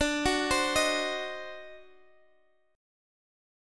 D7b9 Chord